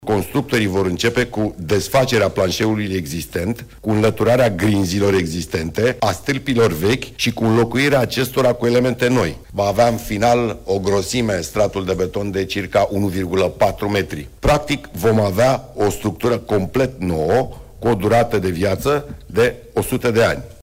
Pe 10 iunie încep lucrările la podul peste râul Dâmbovița, după ce Nicușor Dan a semnat, în ultima zi de mandat ca primar al Capitalei, autorizația de construire, singurul document de care mai era nevoie pentru începerea lucrărilor, a anunțat primarul sectorului 4, Daniel Băluță.